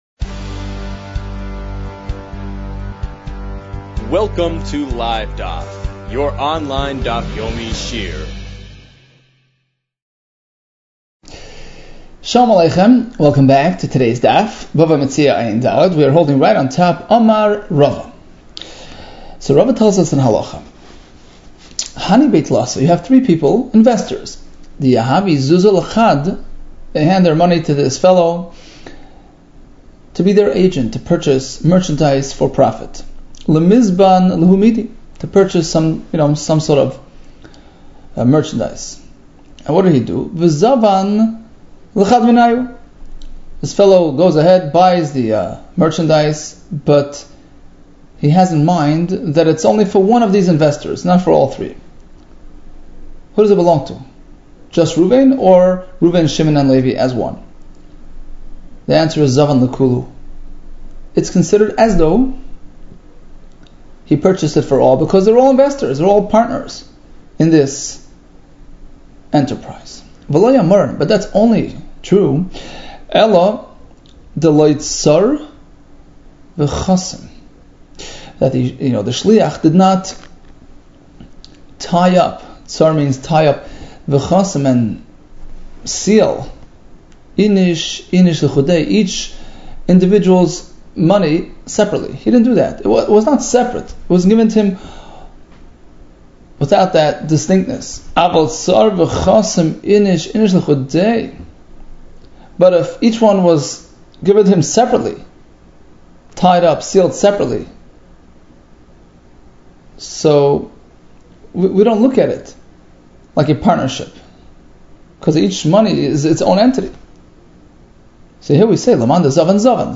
Bava Metzia 73 - בבא מציעא עג | Daf Yomi Online Shiur | Livedaf